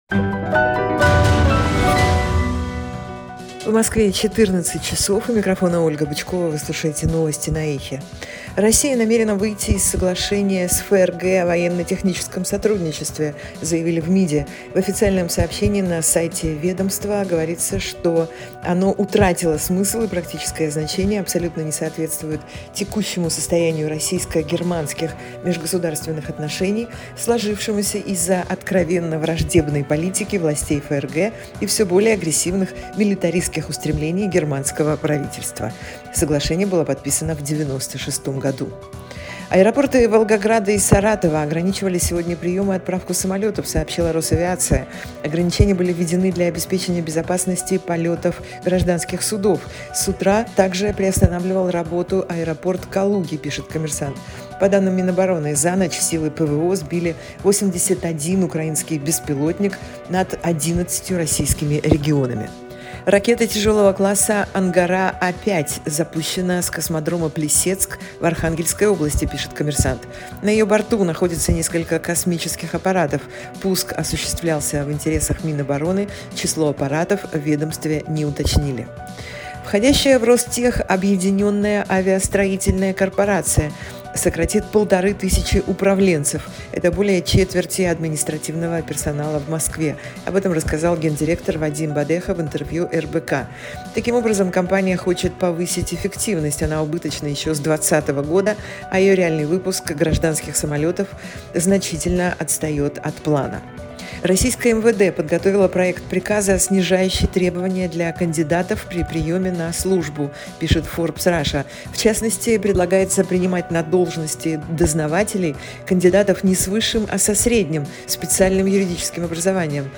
Слушайте свежий выпуск новостей «Эха»
Новости